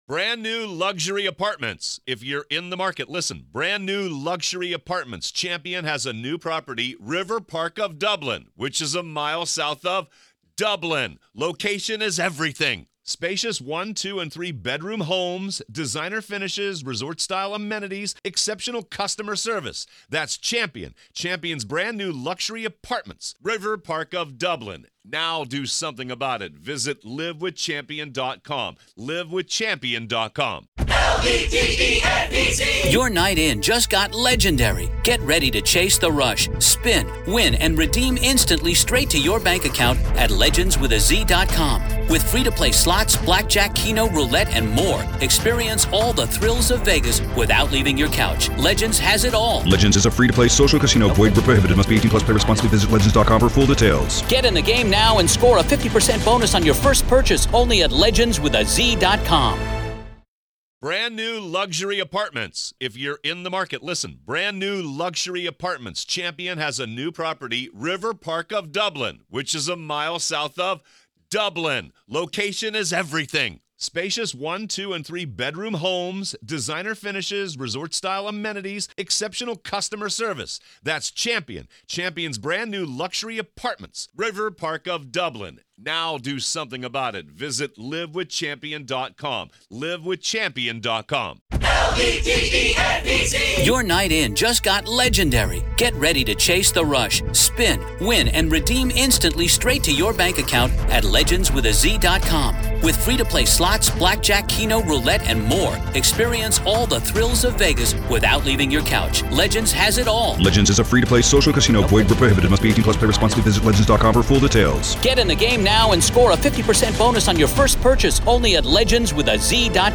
The duo dives deep into the recent Homeland Security raids on Sean "Puffy" Combs' properties, dissecting the ins and outs of a case that's as convoluted as it is sensational. Here’s the skinny on their chat: Collaborative Efforts: It's all hands on deck with federal agencies joining forces in a grand display of bureaucracy at its finest.